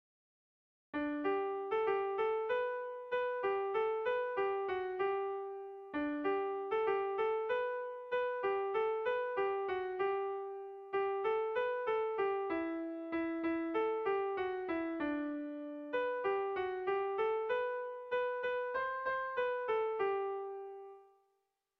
Zuberoa < Euskal Herria
AABD